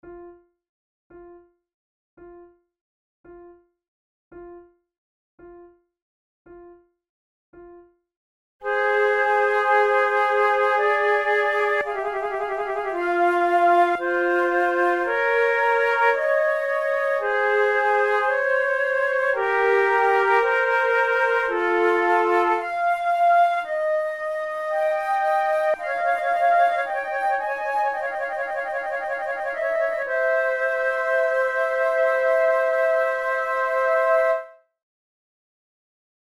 KeyF minor
Tempo56 BPM
Baroque, Sonatas, Written for Flute